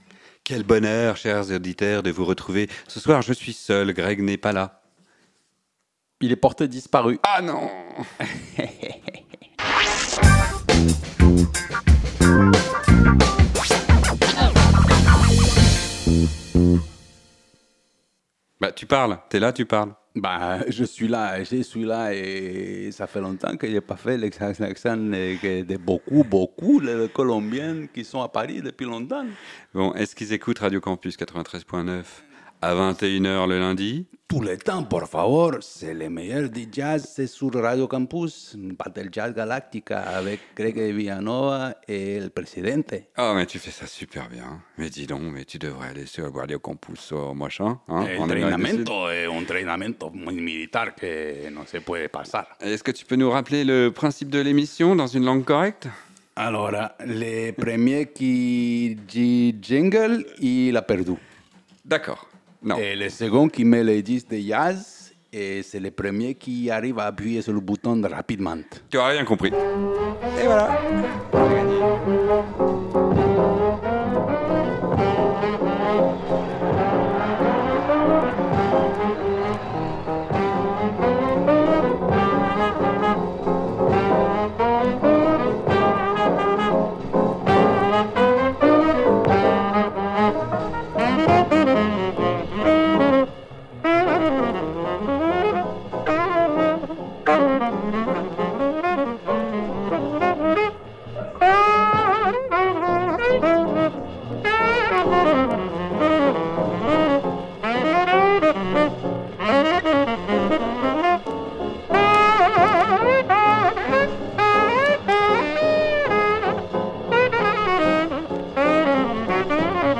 Les murs transpirent, les vinyles crissent.
Classique & jazz